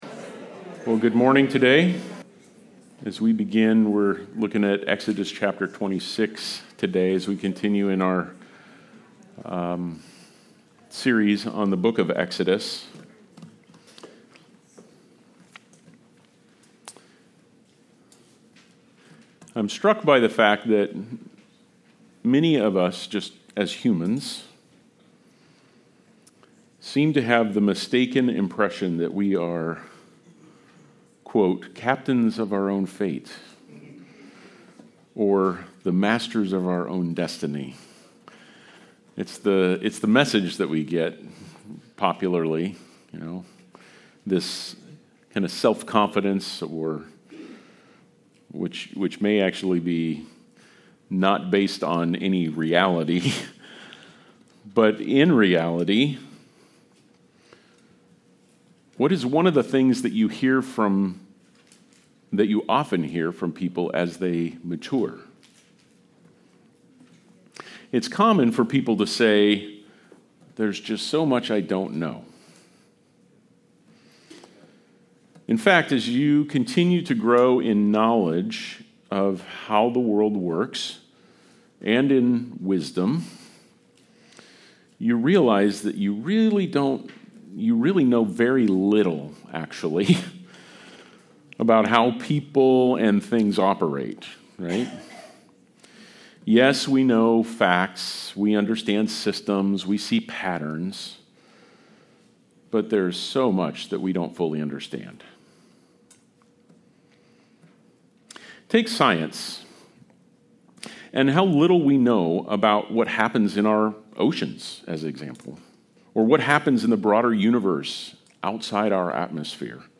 I AM THE LORD Passage: Exodus 26 Service Type: Sunday Service Related « 1 Corinthians 9